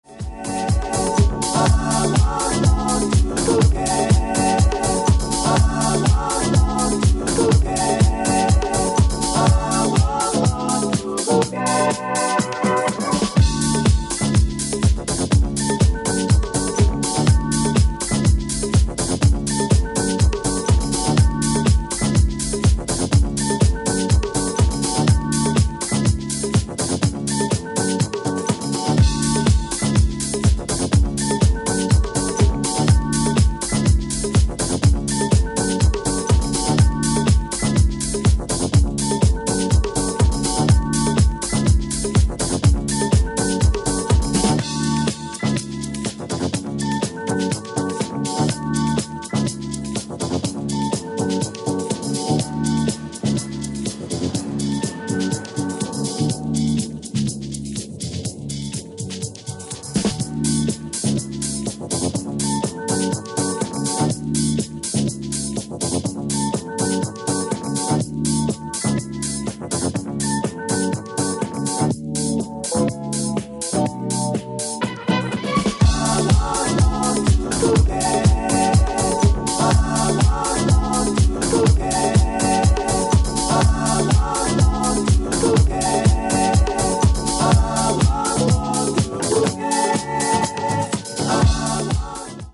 ジャンル(スタイル) DISCO HOUSE / DEEP HOUSE